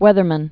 (wĕthər-mən)